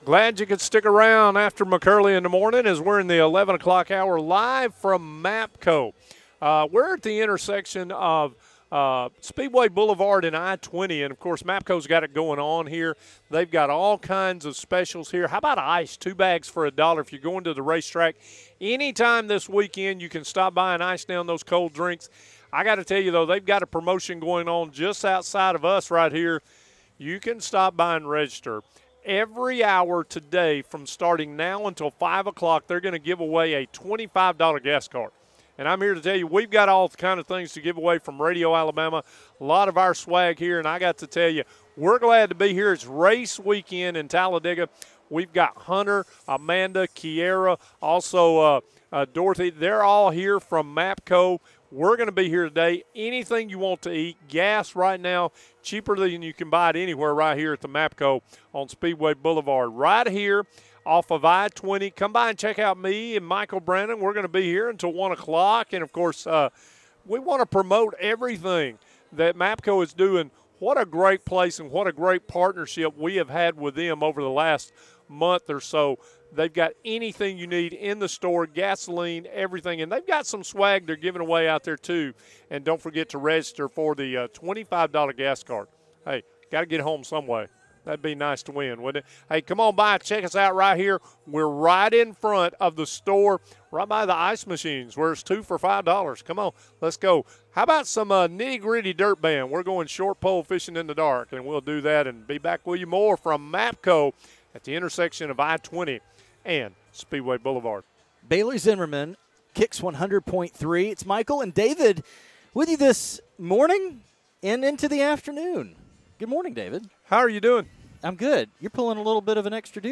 Live from the MAPCO on Speedway Blvd. - Day 1